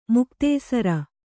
♪ muktēsara